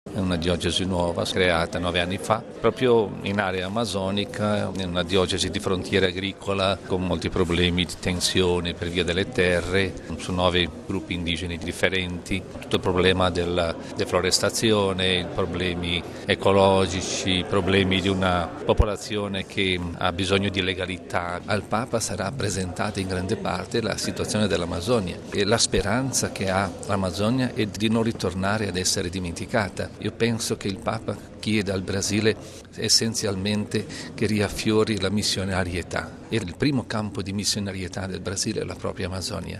E sulla situazione dell’Amazzonia ascoltiamo un altro vescovo salesiano, mons. Franco Dalla Valle, alla guida della diocesi di Juína